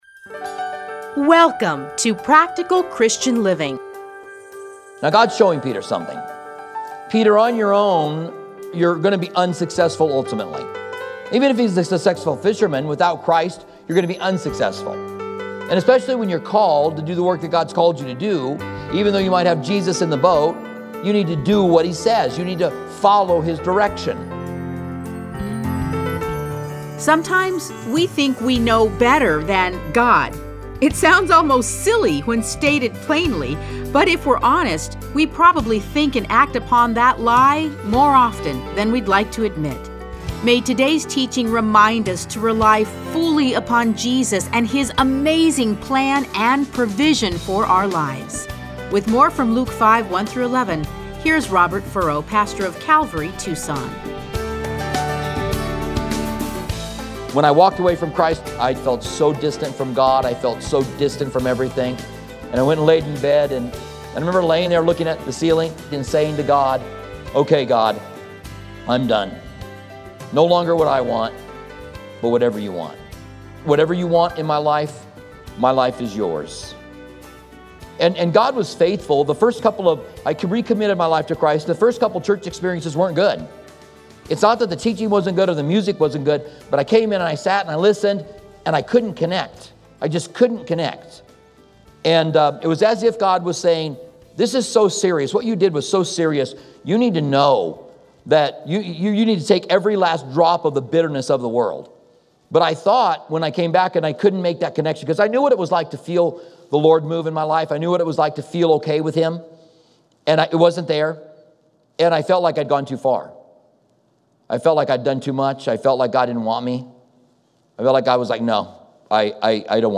Listen to a teaching from Luke 5:1-11.